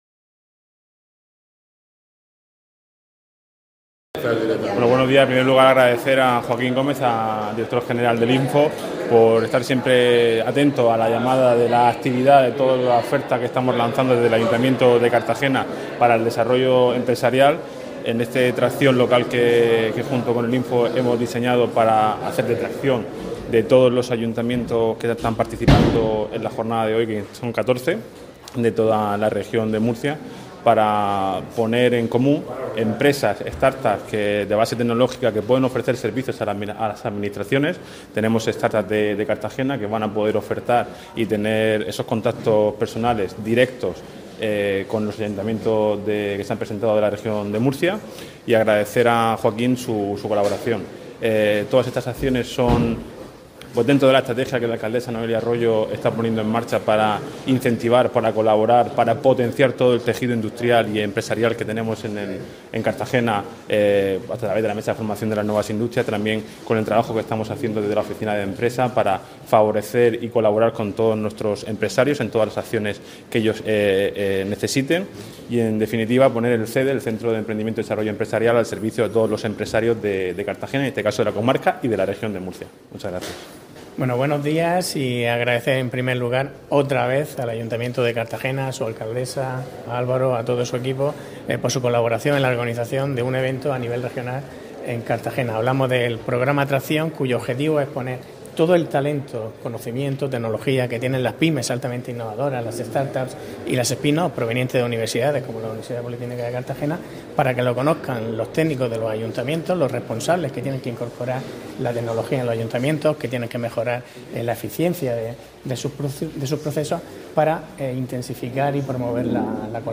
Enlace a Declaraciones de Álvaro Valdés y Joaquín García sobre la Jornada Tracción Local 2025
A la inauguración del evento han asistido el concejal de Empleo, Álvaro Valdés, y el director del INFO, Joaquín García, que explicaban el objeto de este tipo de encuentros, poner en valor el conocimiento de empresas de base tecnológica que pueden ofrecer servicios a las administraciones.